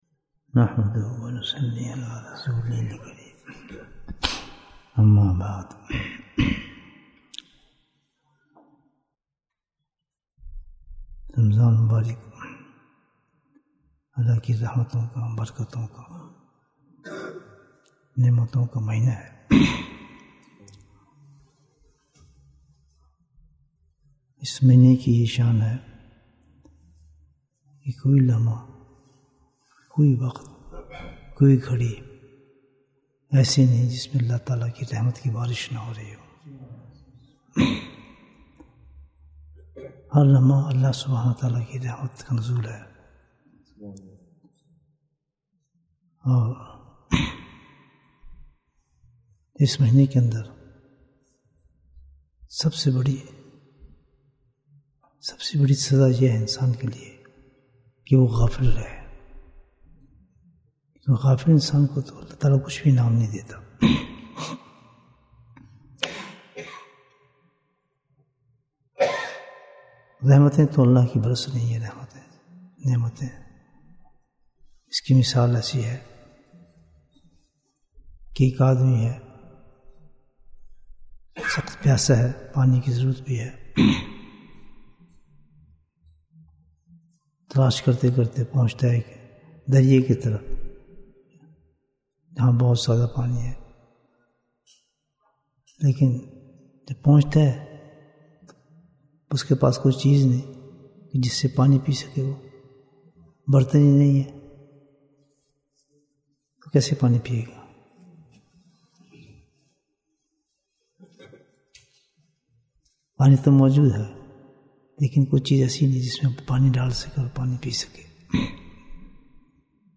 Bayan, 28 minutes25th March, 2023